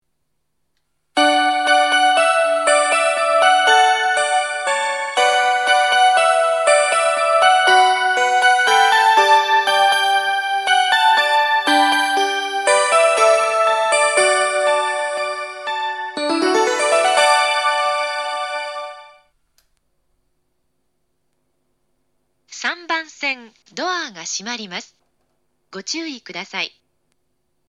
発車メロディー
フルコーラスです。